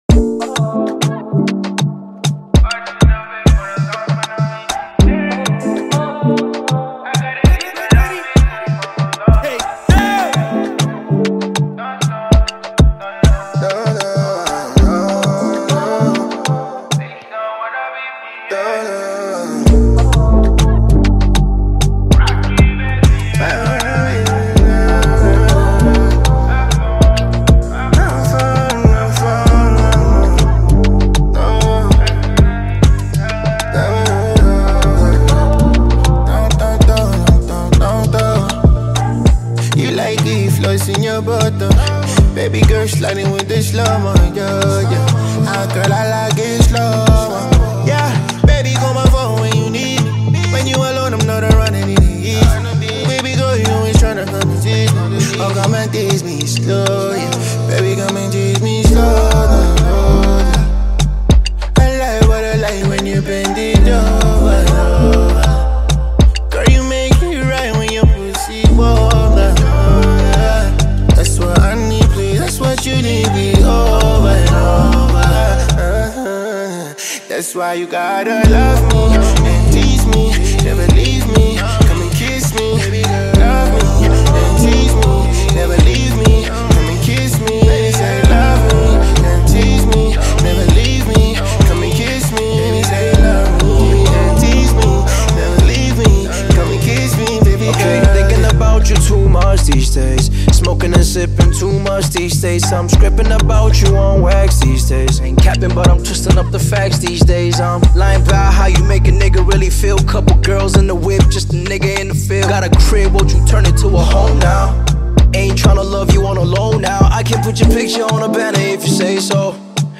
hip-hop song